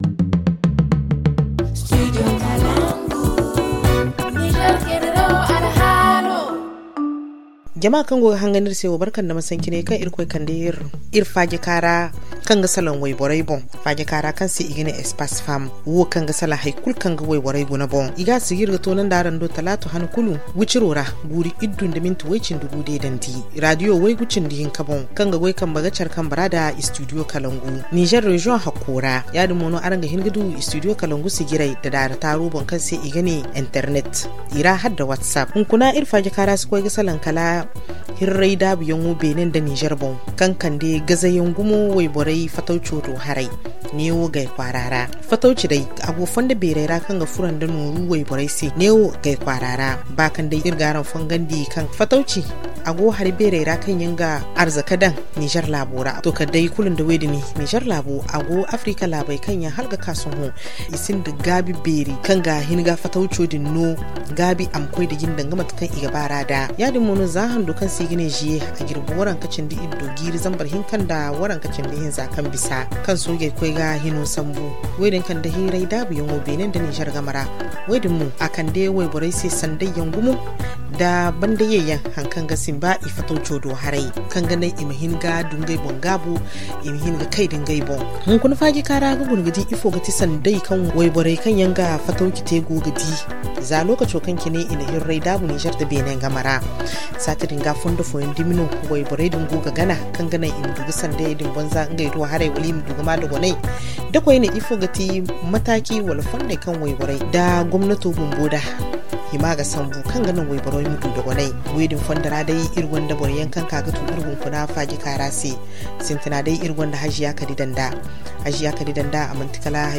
[Rediffusion] Fermeture de la frontière entre le Bénin et le Niger : quelles alternatives pour le commerce des femmes à Gaya - Studio Kalangou - Au rythme du Niger